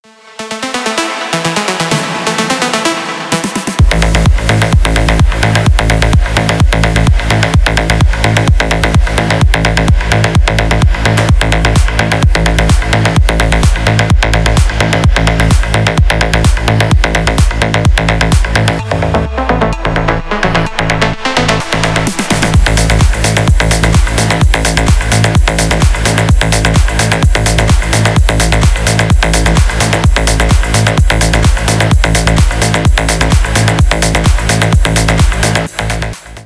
Громкие звонки, звучные рингтоны